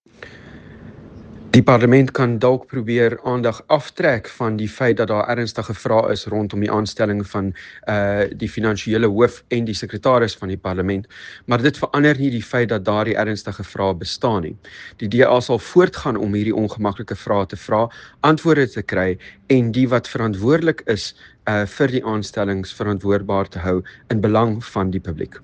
Afrikaans soundbite by George Michalakis MP.